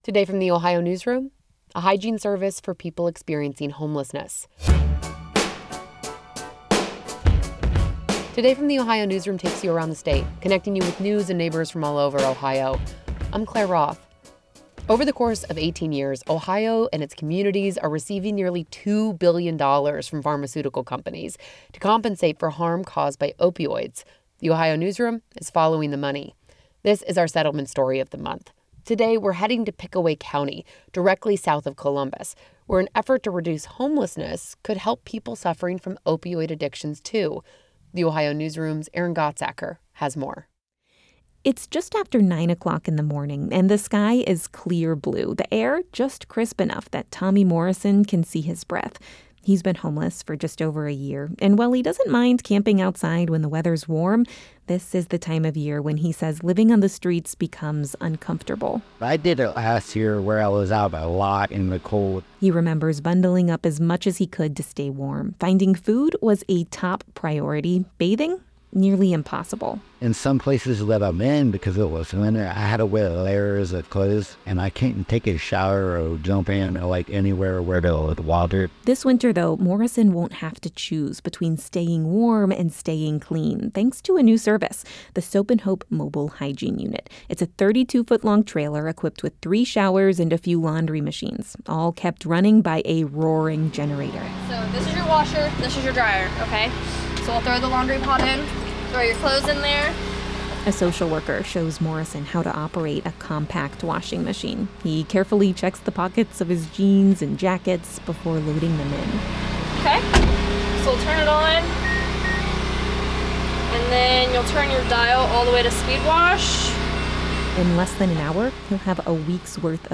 It’s equipped with three showers and a few laundry machines, all kept running by a roaring generator.
A peer supporter shows him how to turn it on, and the machine whirs to life.